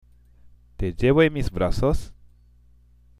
（テジェボ　エンミス　ブラソス？）